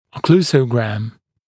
[ə’kluːsəugræm][э’клу:соугрэм]окклюзиограмма